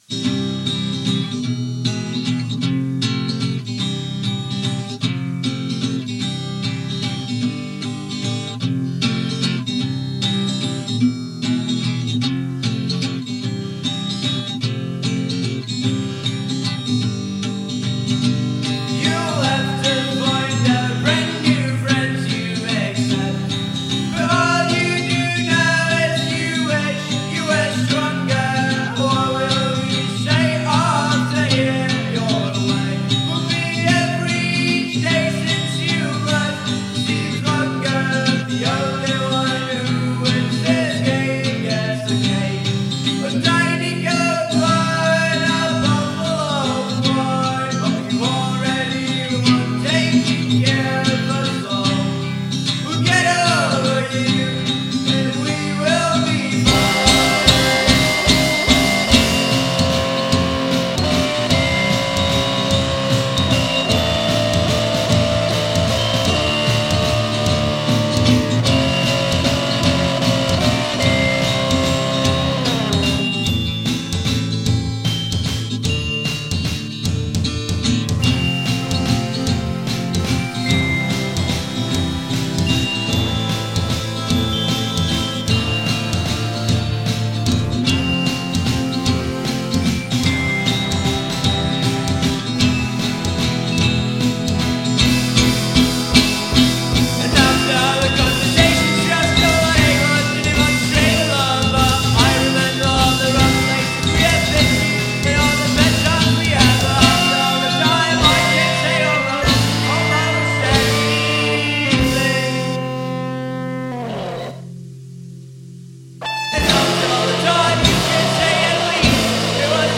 Žánr: Indie/Alternativa
guitar, beats, vocals
synths, piano, toy piano, vocals